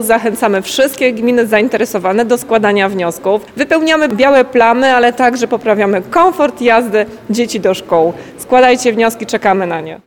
– zachęca Anna Brzezińska, członkini zarządu województwa mazowieckiego.